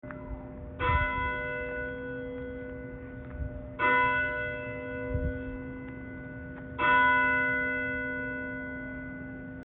Download Church Bell sound effect for free.
Church Bell